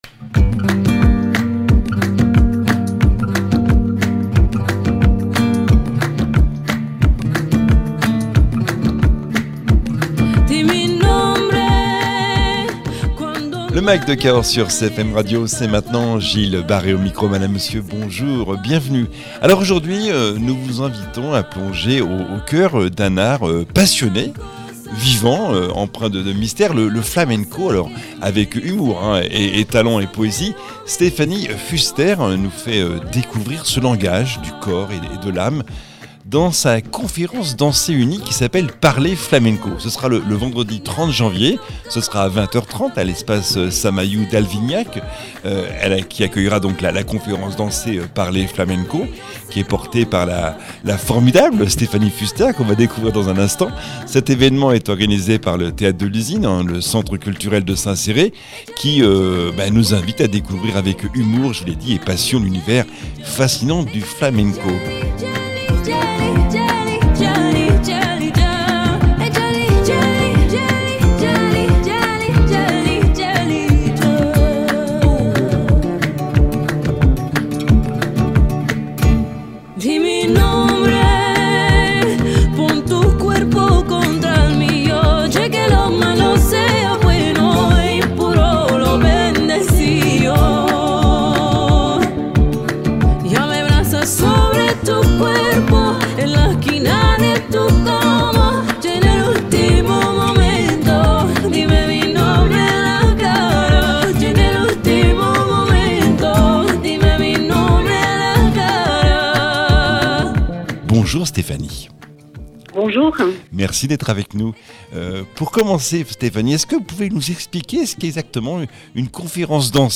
danseuse de flamenco, chorégraphe, interprète